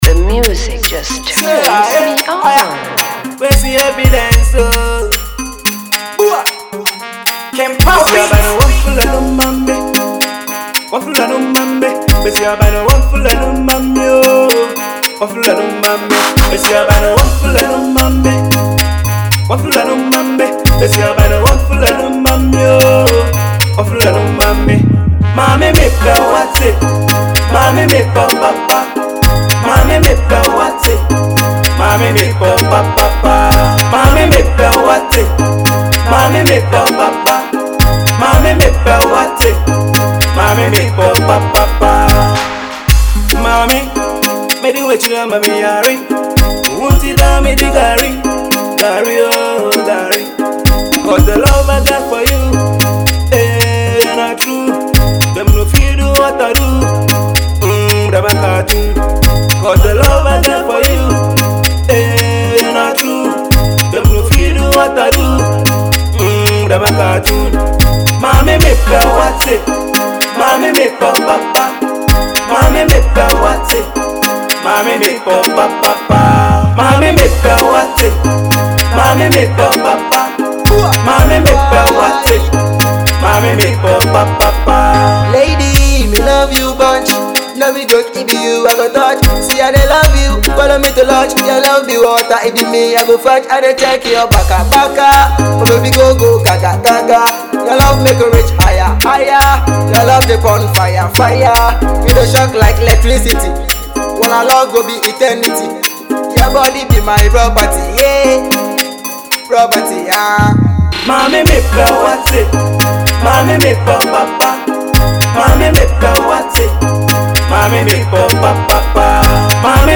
jamming love banger
Afrobeat Reggae/Dancehall